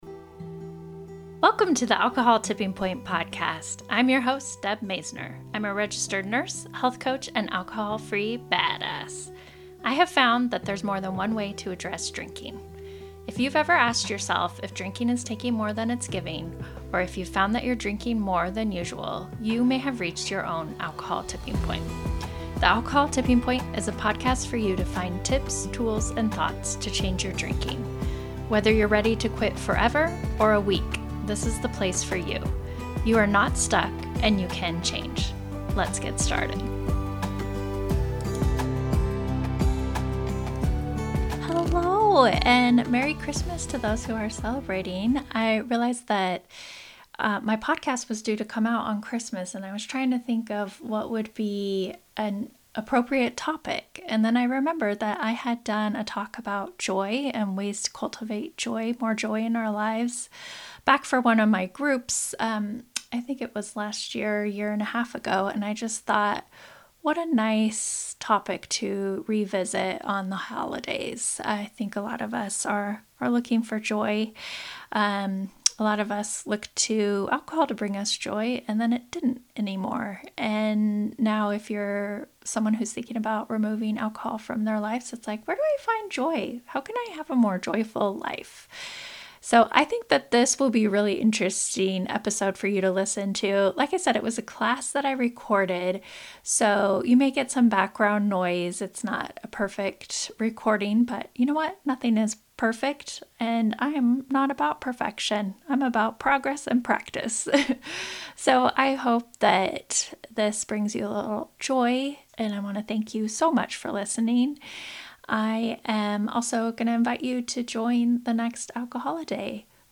In this episode, I share a recording of a class I gave about how to cultivate more joy in our lives. I talk about what joy really means, how it can help our physical and mental health, barriers to joy, and ways to enhance everyday joy.